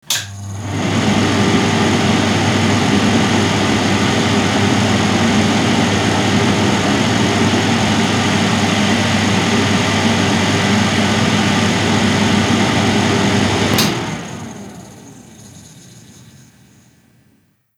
Extractor de aire de un cuarto de baño
extractor
Sonidos: Hogar